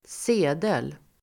Uttal: [s'e:del]